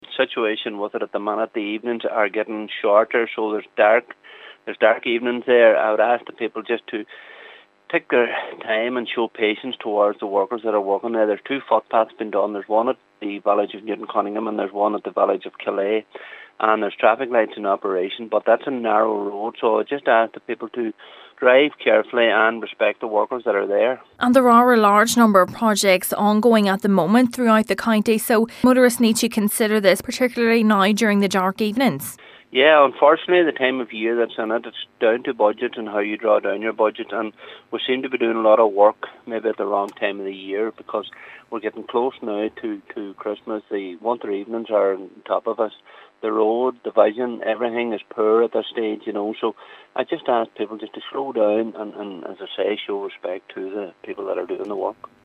In relation to works being carried out on the Killea to Newtowncunningham road, local Councillor Paul Canning is calling on drivers to exercise caution on approach: